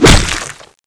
hit2.wav